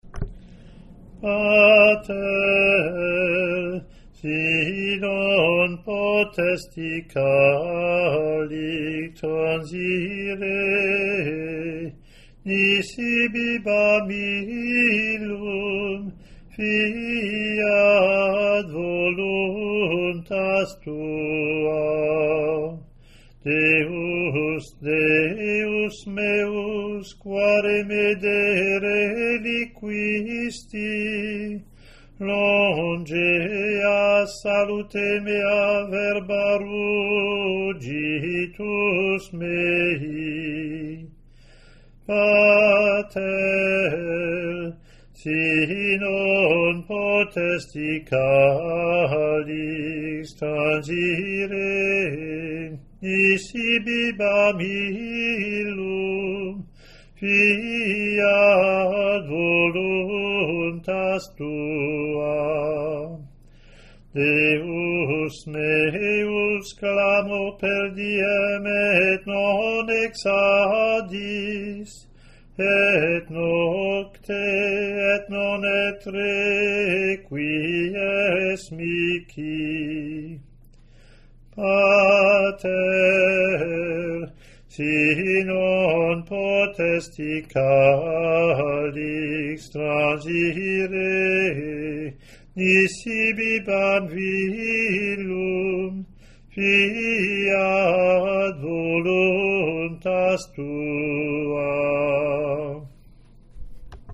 Latin antiphon + verses)